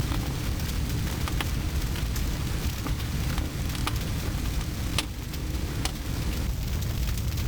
Fire_Mono_01.ogg